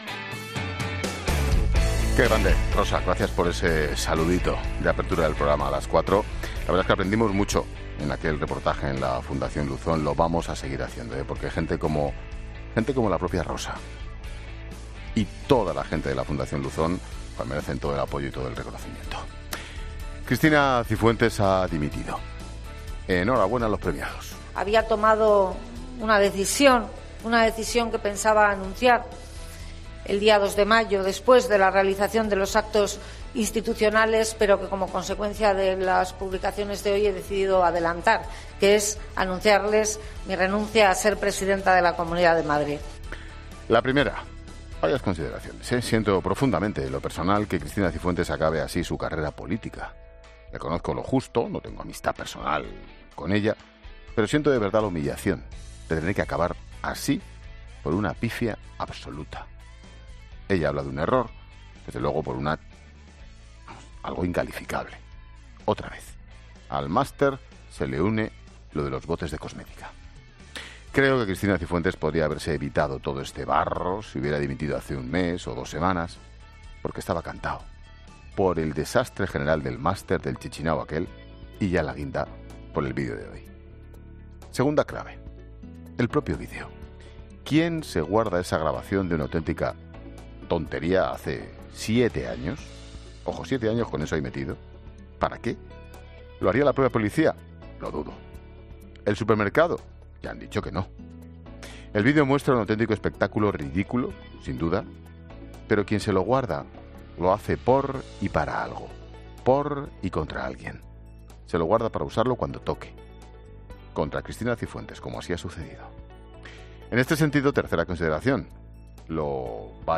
Monólogo de Expósito
El comentario de Ángel Expósito por la dimisión de Cristina Cifuentes como presidenta de la Comunidad de Madrid.